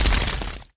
evil_dragon_hit2.wav